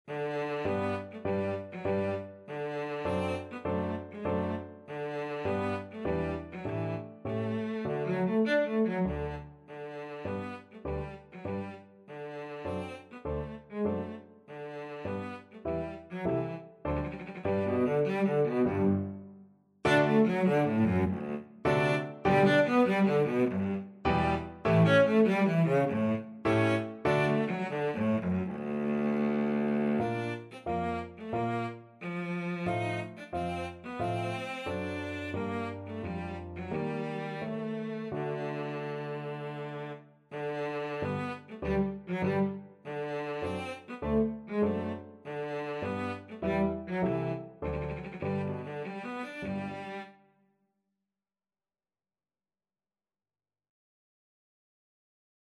2/4 (View more 2/4 Music)
D3-Eb5